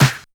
100 CLAP SNR.wav